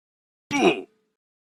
Minecraft Death Sound Effect.mp3